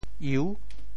侑 部首拼音 部首 亻 总笔划 8 部外笔划 6 普通话 yòu 潮州发音 潮州 iu6 文 中文解释 侑〈动〉 (形声。